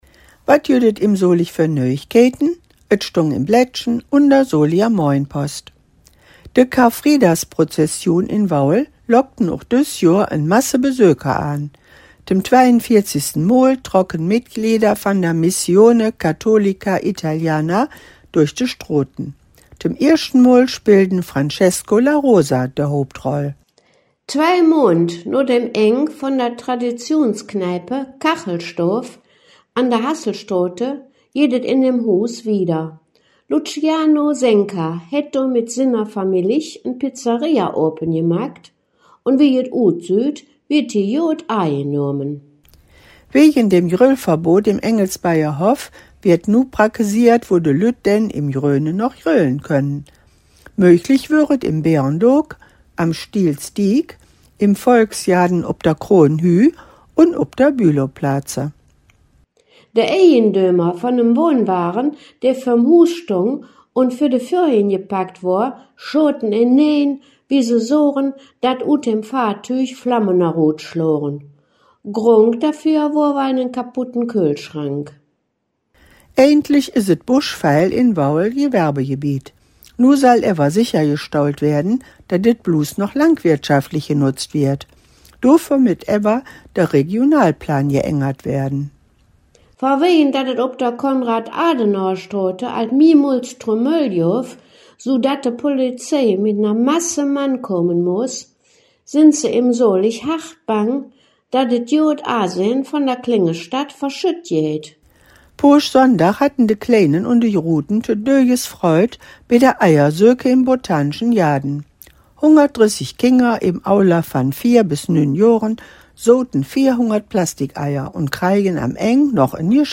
Der in Solingen gesprochene Dialekt wird Solinger Platt genannt.
Zugleich wird im Solinger Platt aber auch die Nähe zum ripuarischen Sprachraum (vor allem durch das Kölsch bekannt) hörbar.